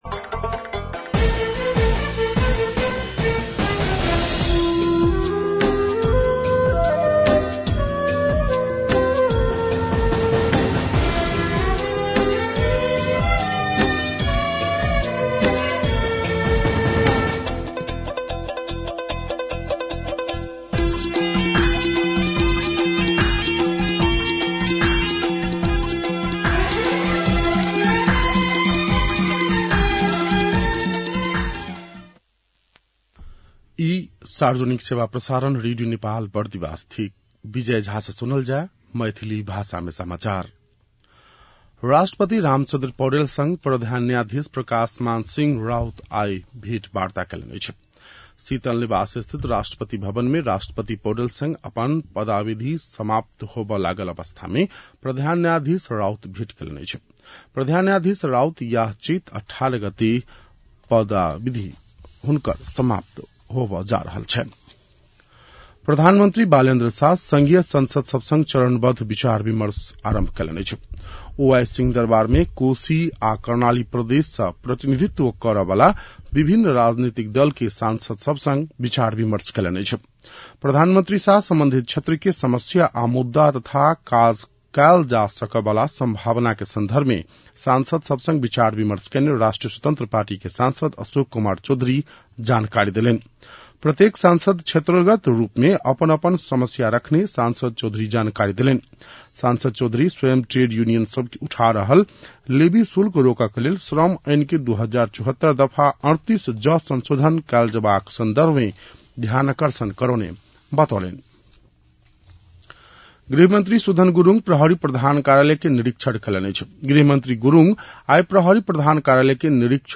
मैथिली भाषामा समाचार : १६ चैत , २०८२